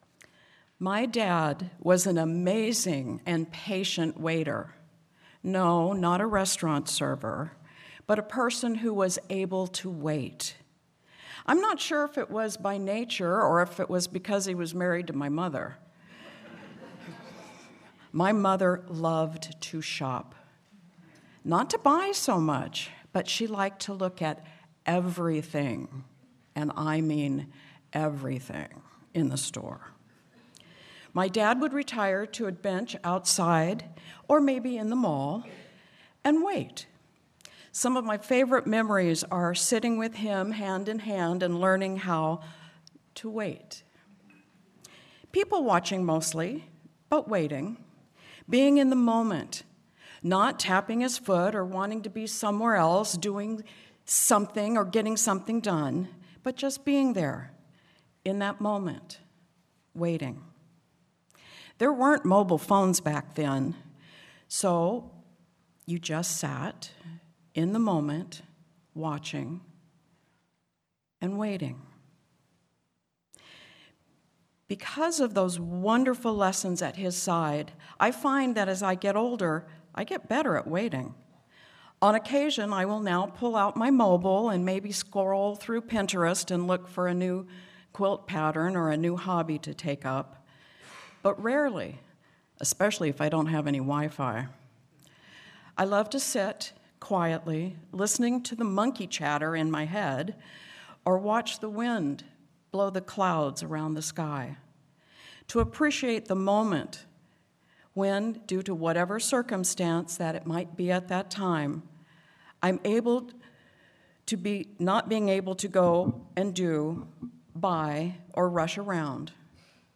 00:00 to 03:34 – Reflection 03:34 to 04:16 – Reading 04:16 to 23:50 – Sermon